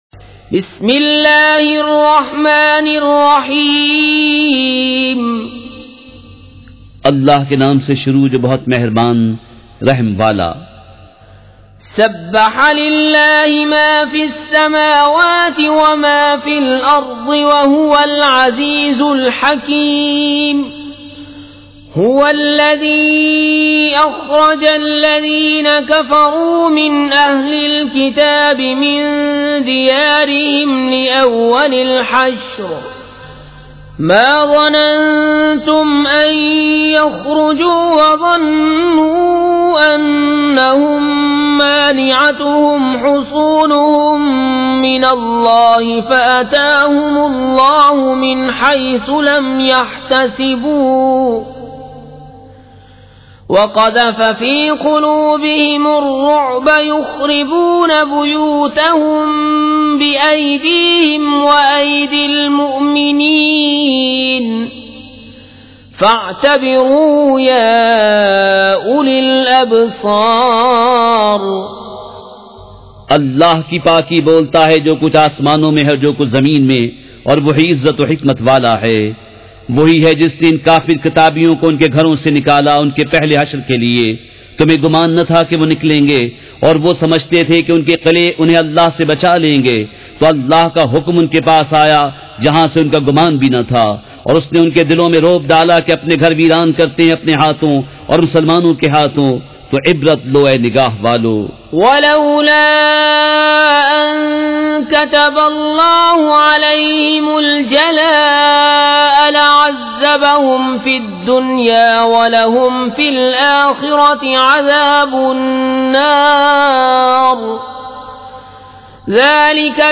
سورۃ الحشر مع ترجمہ کنزالایمان ZiaeTaiba Audio میڈیا کی معلومات نام سورۃ الحشر مع ترجمہ کنزالایمان موضوع تلاوت آواز دیگر زبان عربی کل نتائج 1749 قسم آڈیو ڈاؤن لوڈ MP 3 ڈاؤن لوڈ MP 4 متعلقہ تجویزوآراء